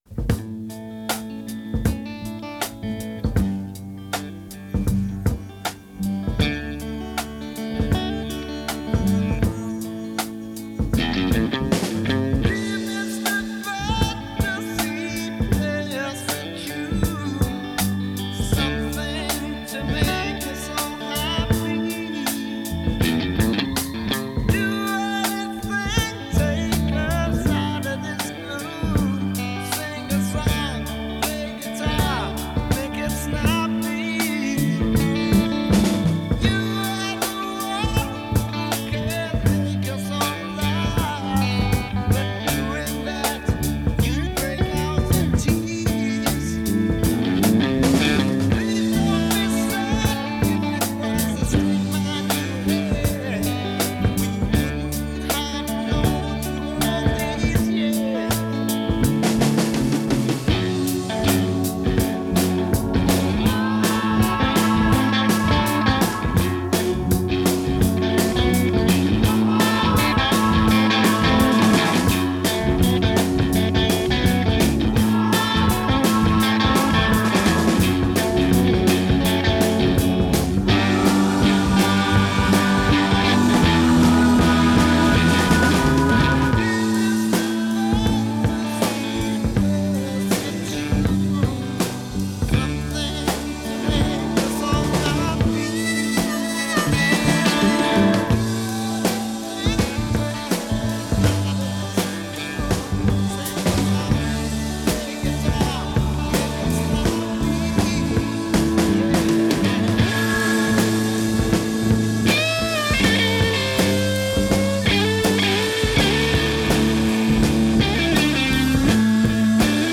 rock music Acid rock